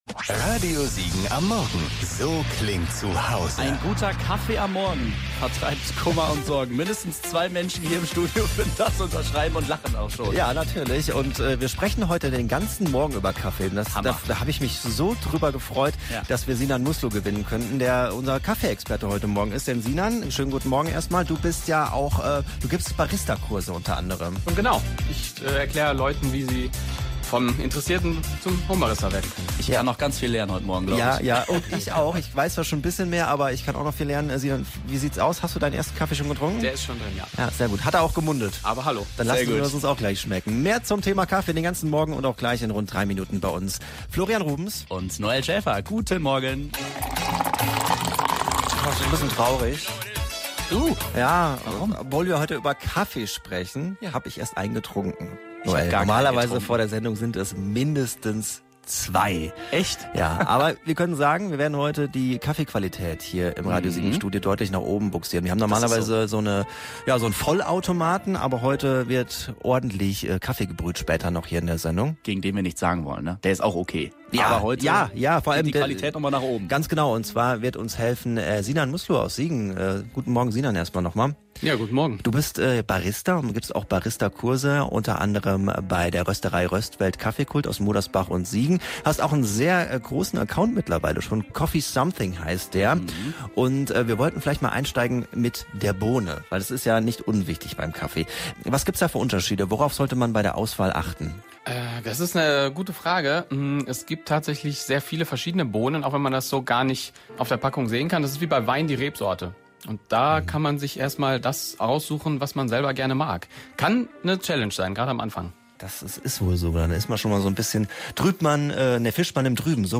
Kaffee-Donnerstag in der Morningshow - Podcastformat